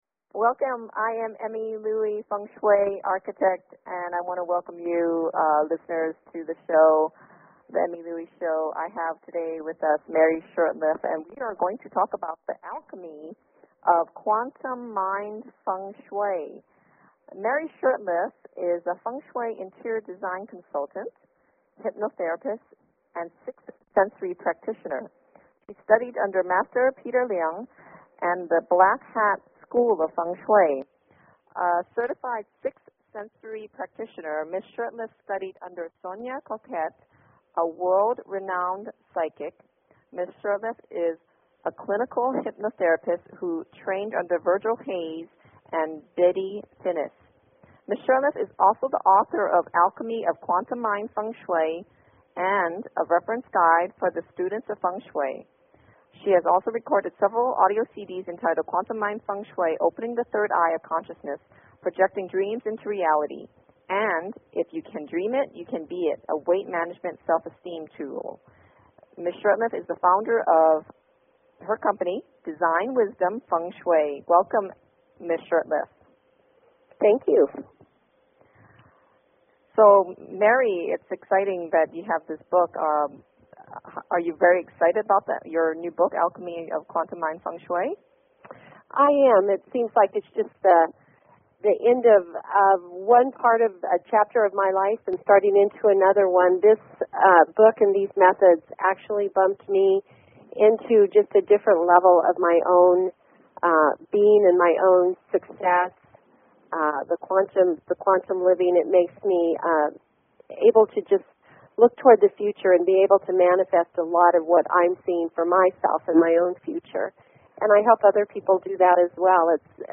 Hypnotherapy: Auto-Programming & Regeneration Cycles with a 15-Minute Hypnotherapy Session at the End
is an Internet Radio Show on Green and Sustainable Architecture, Healthy Living and Feng Shui.